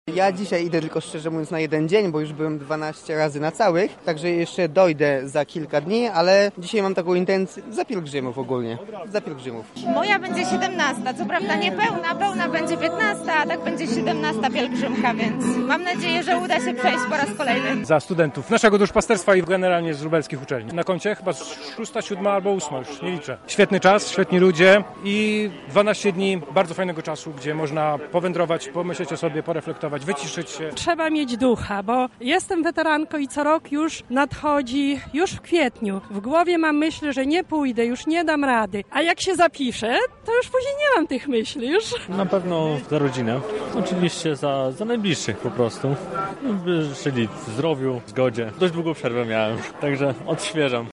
O to, co skłoniło lubelskich wiernych do udziału w pielgrzymce zapytał nasz reporter: